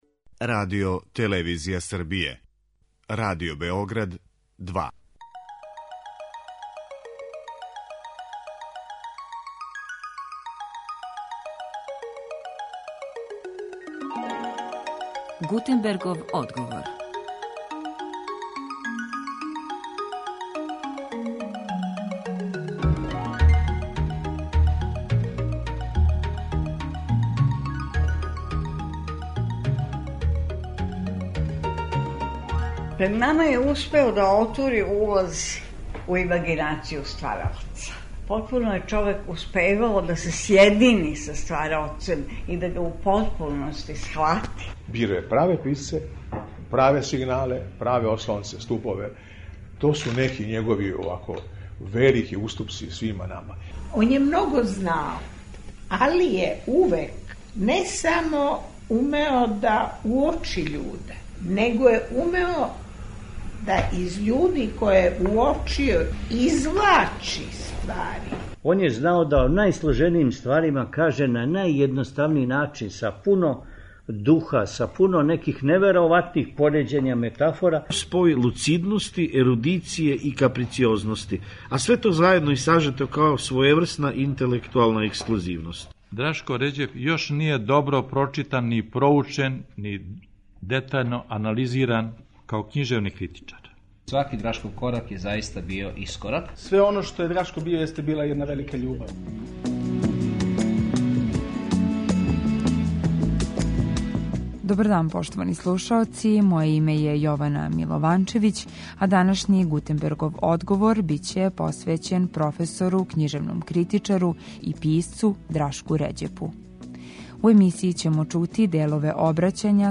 У емисији ћемо чути делове обраћања са округлог стола који је организован у Вуковој задужбини, а на коме су говорили ученици, поштоваоци и пријатељи Драшка Ређепа.